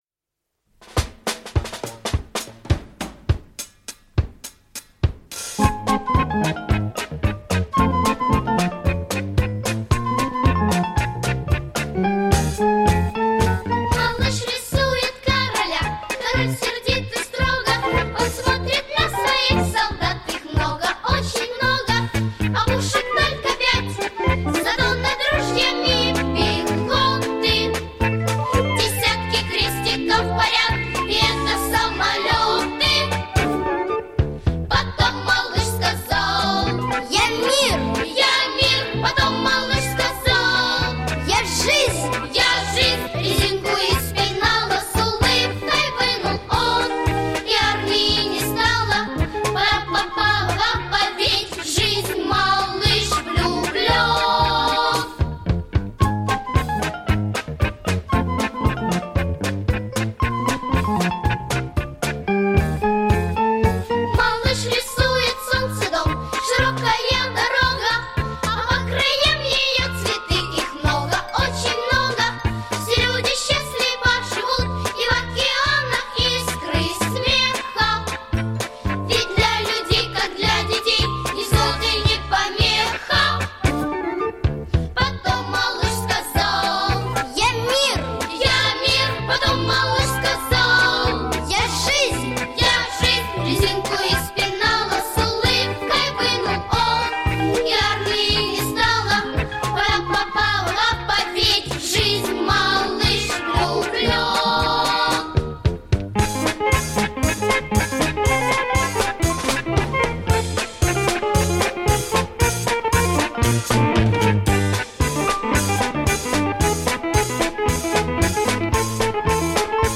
• Категория: Детские песни
советские детские песни